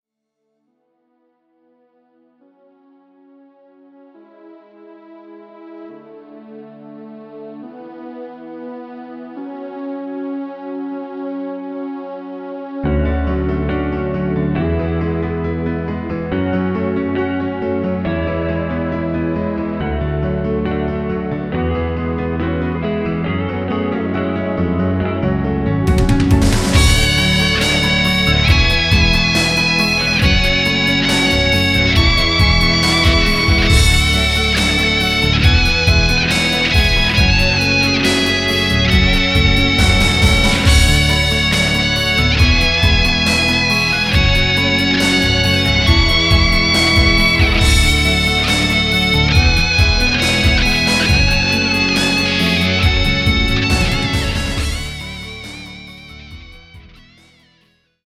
( Instrumental )